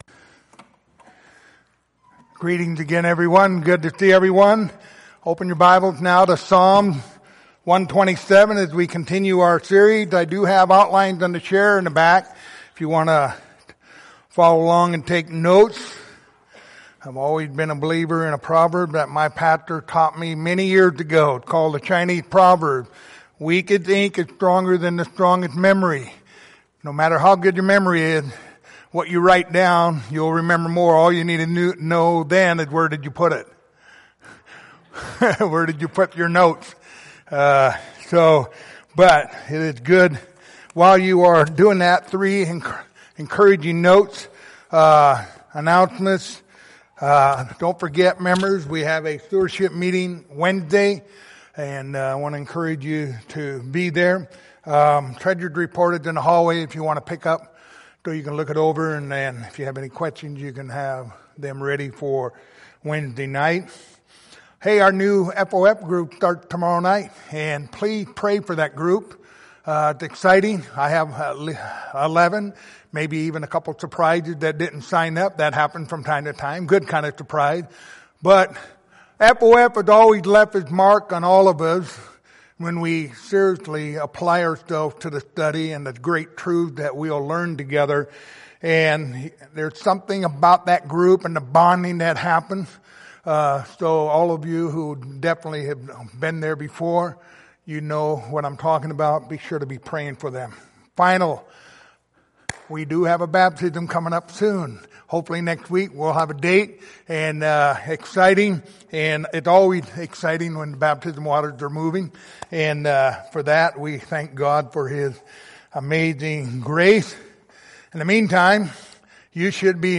Passage: Psalm 127:1-5 Service Type: Sunday Evening